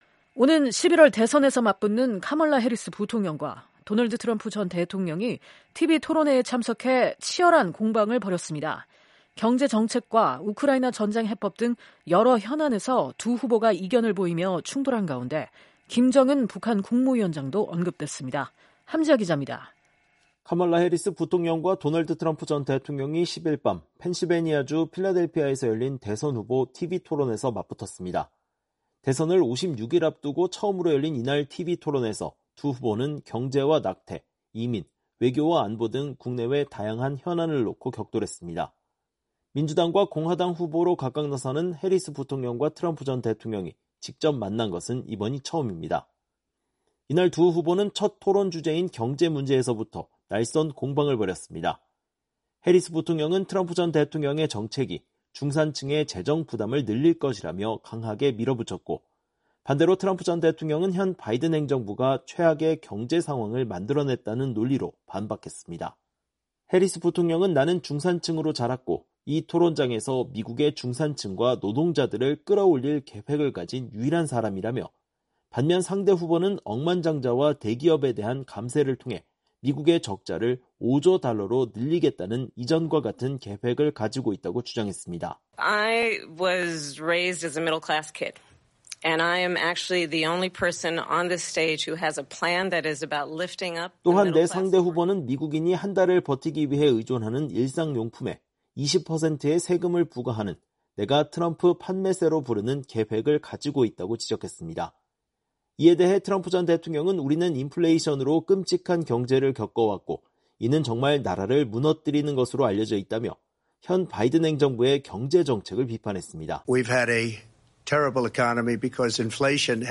[녹취: 해리스 부통령] “I was raised as a middle-class kid, and I am actually the only person on this stage who has a plan that is about lifting up the middle class and working people of America…
[녹취: 트럼프 전 대통령] “We’ve had a terrible economy because inflation has, which it’s really known as a country buster...